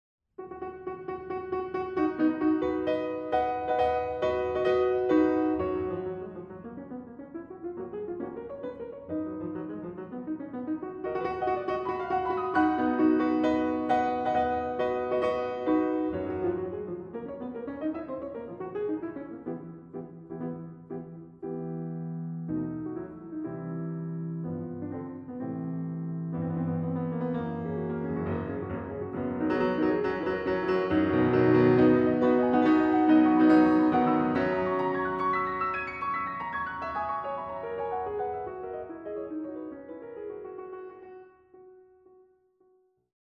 Exquisite sounds well-recorded; excellent booklet.
Piano